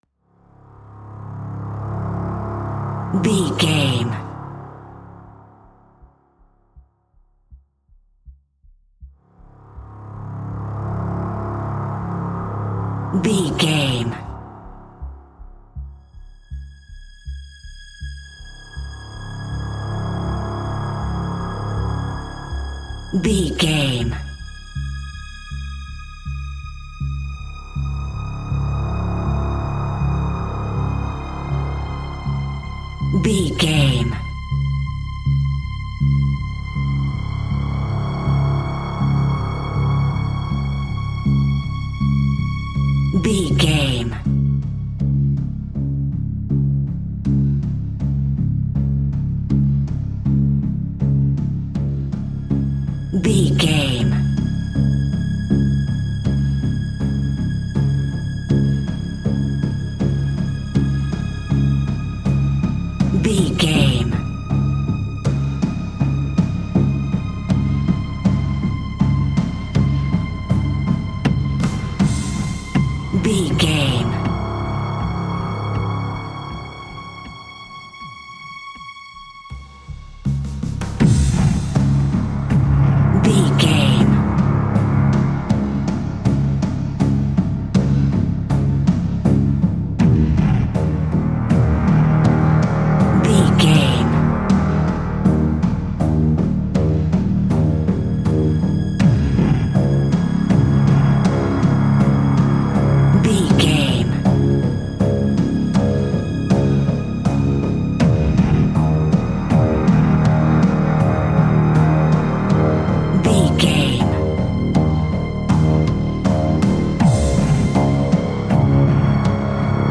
Aeolian/Minor
C#
ominous
dark
haunting
eerie
strings
drums
synthesiser
horror music
Horror Pads
Horror Synths